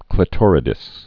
(klĭ-tôrĭ-dĭs, klī-)